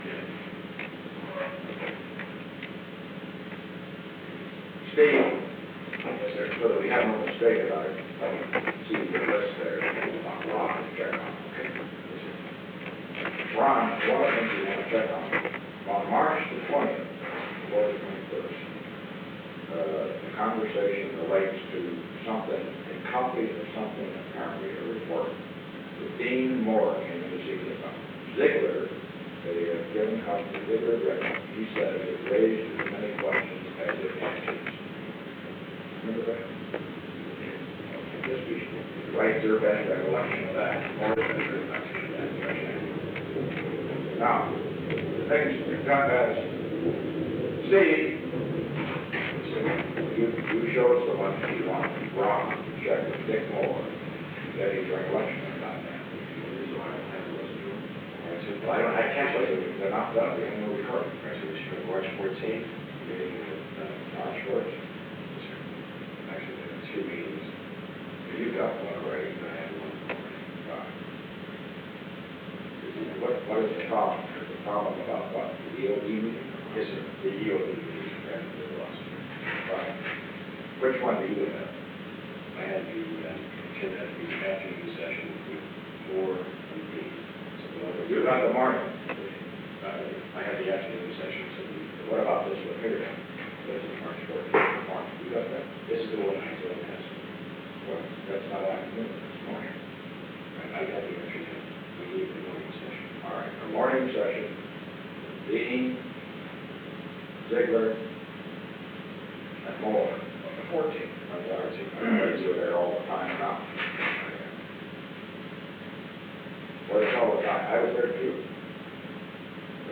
Secret White House Tapes
Conversation No. 442-54
Location: Executive Office Building